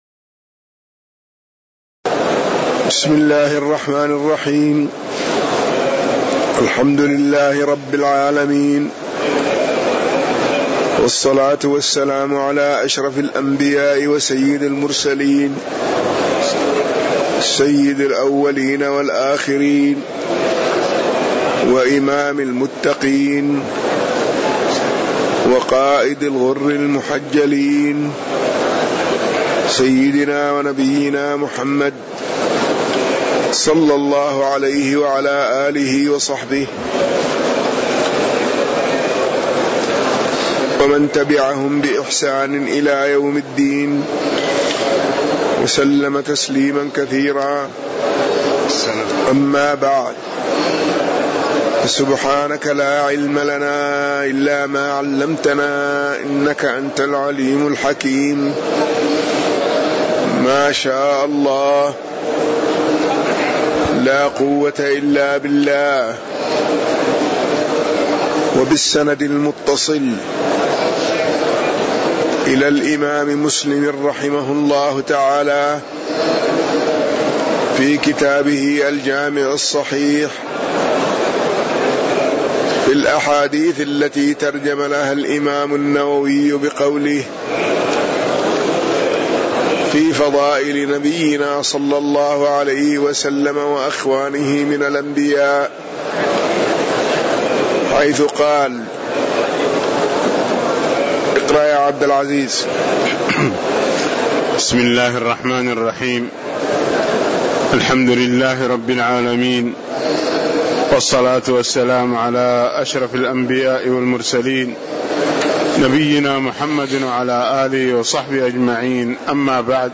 تاريخ النشر ٢١ جمادى الآخرة ١٤٣٧ هـ المكان: المسجد النبوي الشيخ